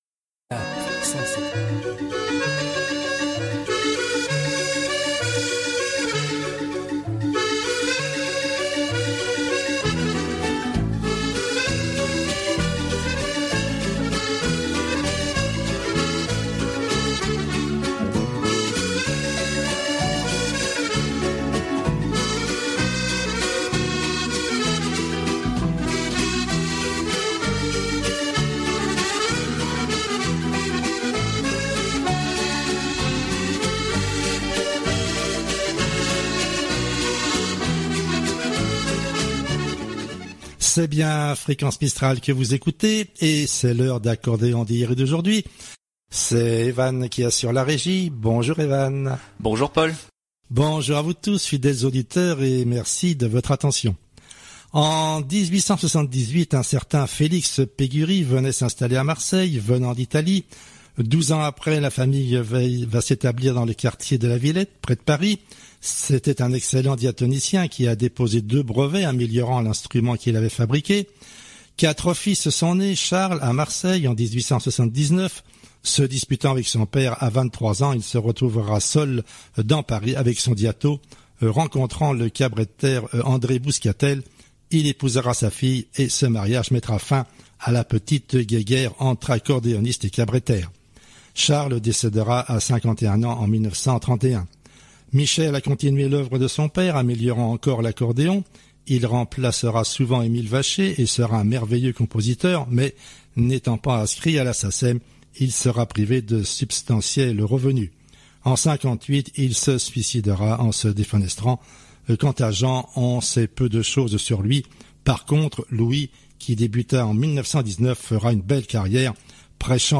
Amateurs d’accordéon bonjour